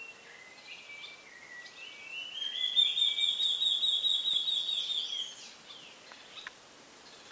BORRALHARA-ASSOBIADORA
Nome em Inglês: Large-tailed Antshrike
Canto
Local: RPPN Corredeiras do Rio Itajaí, Itaiópolis SC